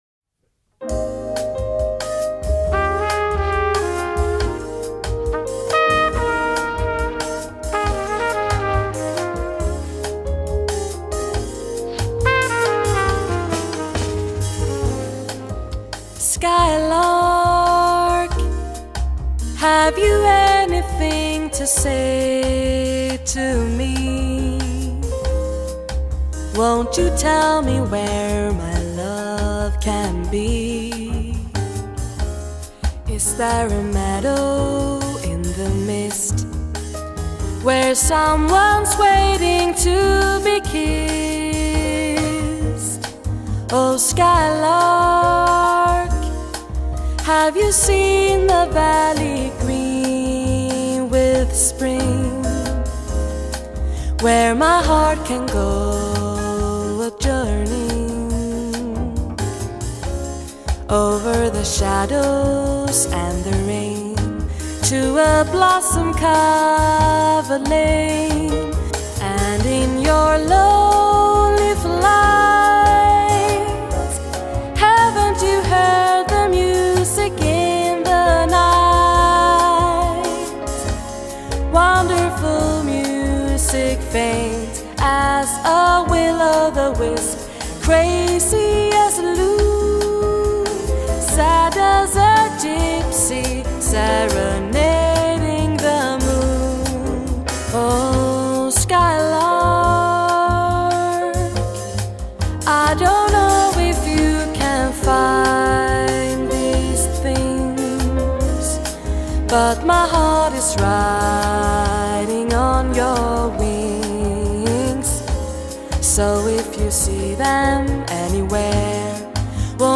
• Jazzband